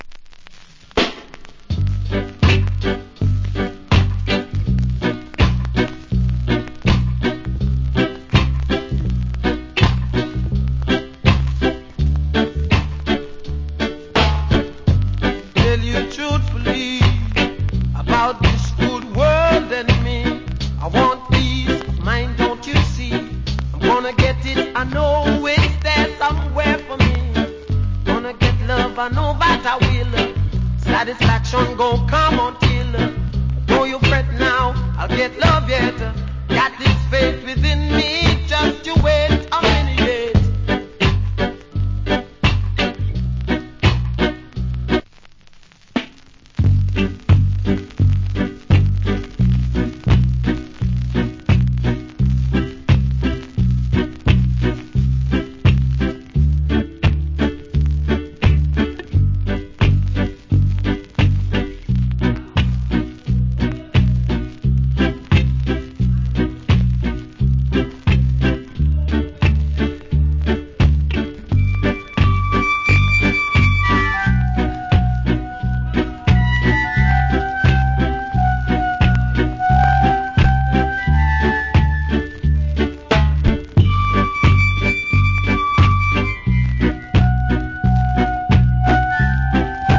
Early Reggae Vocal.